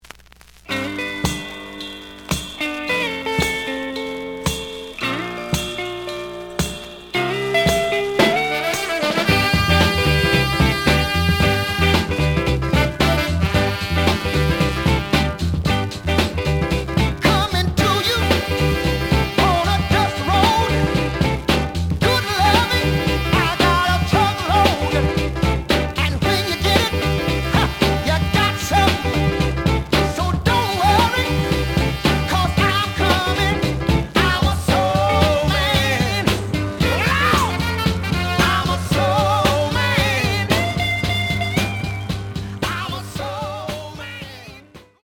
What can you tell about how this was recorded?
The audio sample is recorded from the actual item. Slight noise on both sides.